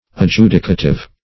Adjudicative \Ad*ju"di*ca*tive\, a.